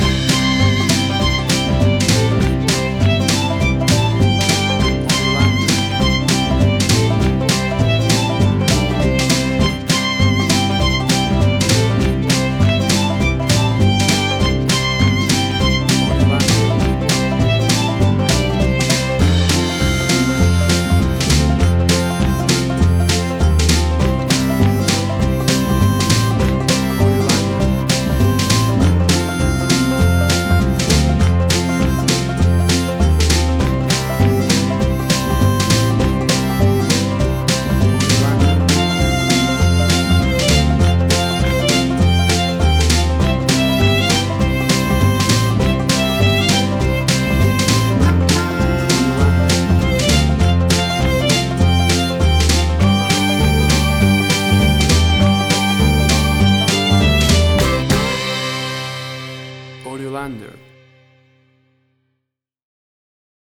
WAV Sample Rate: 16-Bit stereo, 44.1 kHz
Tempo (BPM): 100